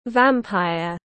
Vampire /’væmpaiə/